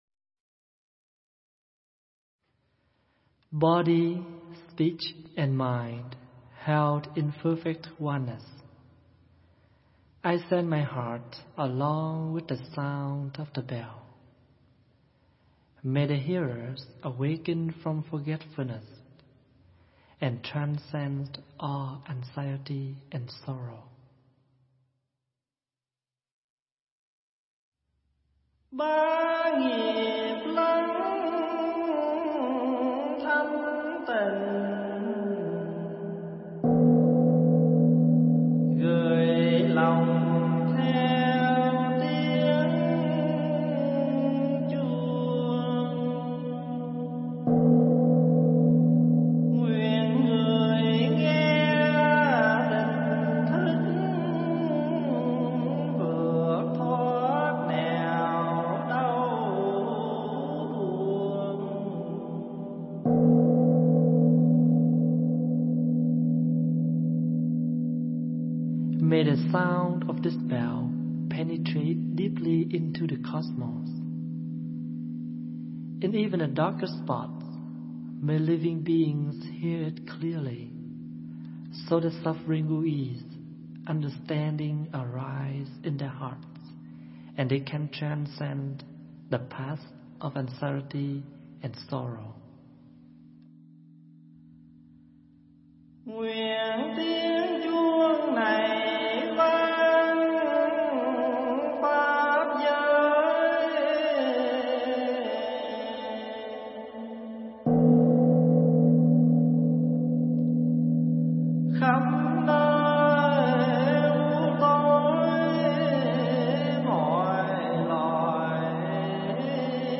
Nghe Mp3 thuyết pháp Thờ Phật Tại Nhà